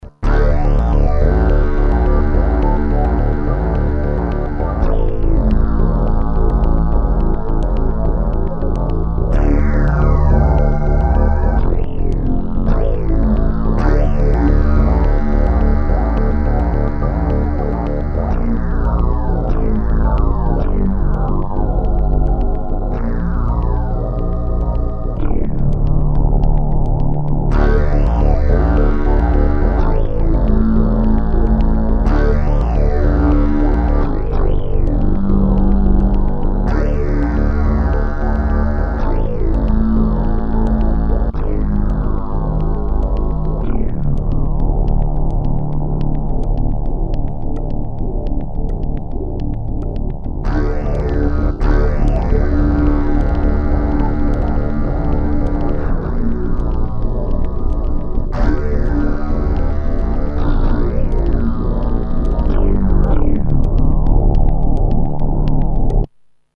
bass
Synthesis: PCM rompler